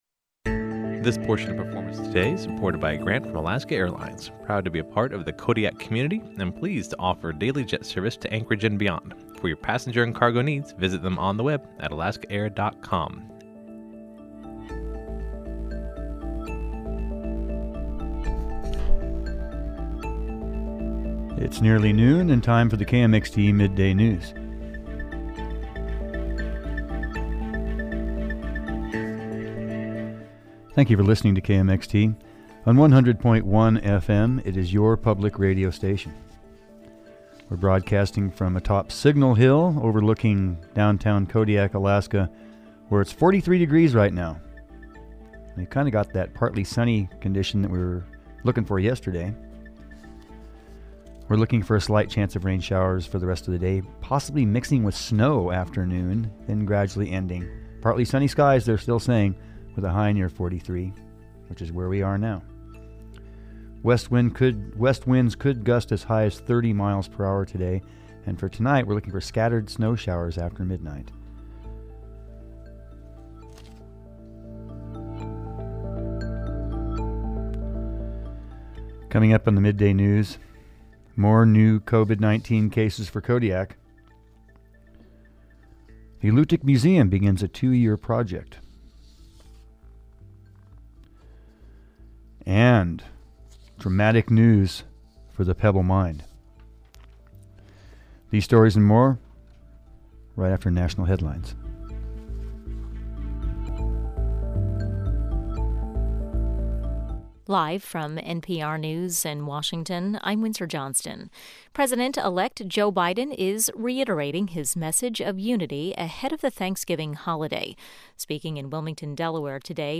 Newscast–Wednesday, November 25, 2020